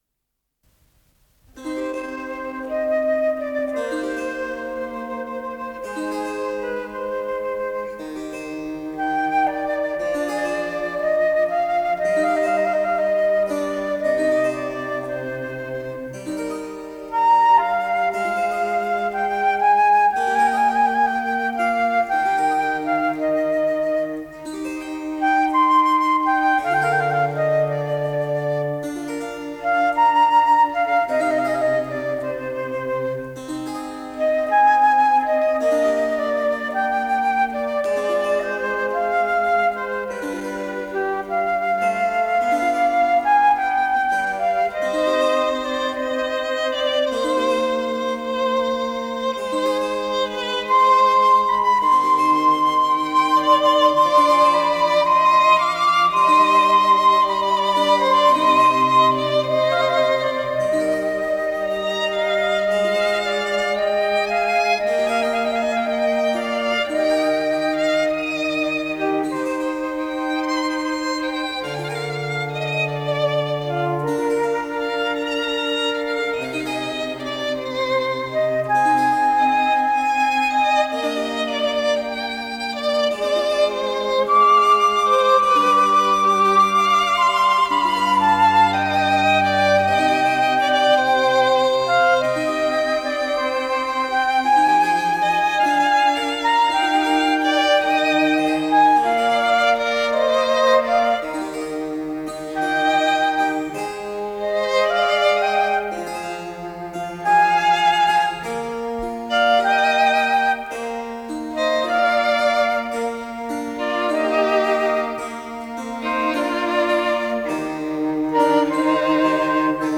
с профессиональной магнитной ленты
ИсполнителиАнсамбль "Барокко"
ВариантДубль моно